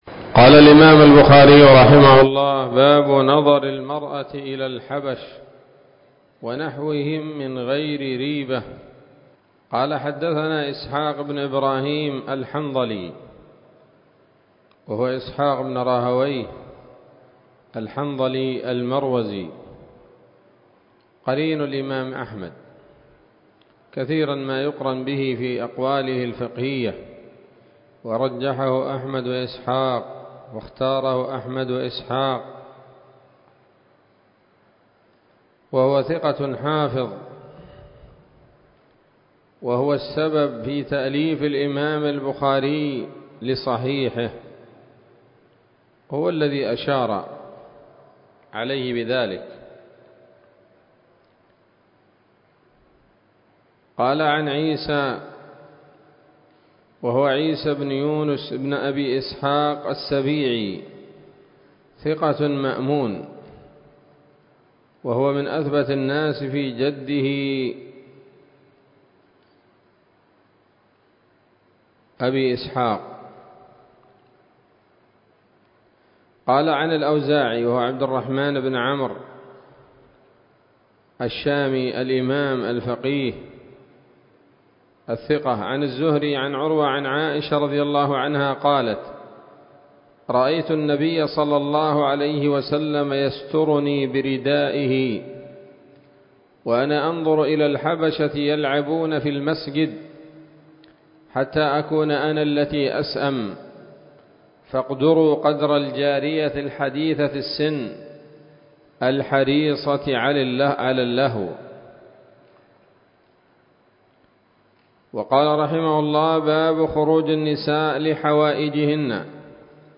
الدرس الحادي والتسعون من كتاب النكاح من صحيح الإمام البخاري